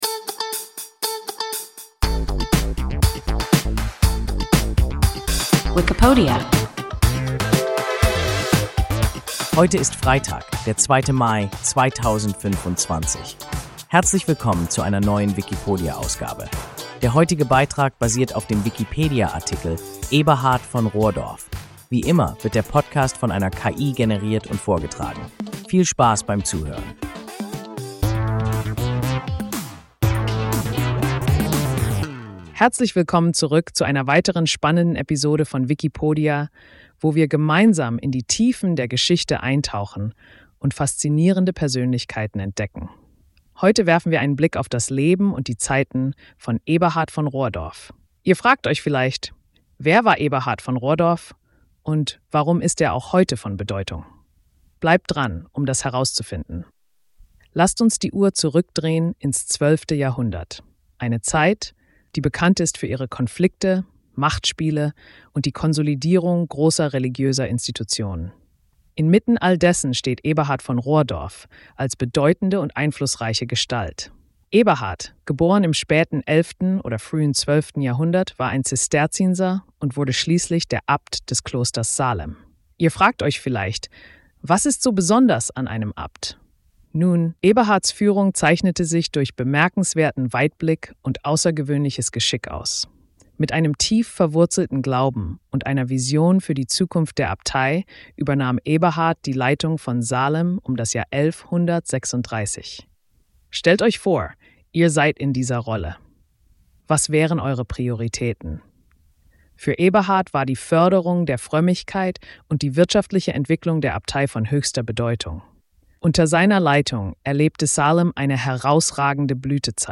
Eberhard von Rohrdorf – WIKIPODIA – ein KI Podcast